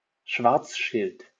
Karl Schwarzschild (German: [kaʁl ˈʃvaʁtsʃɪlt]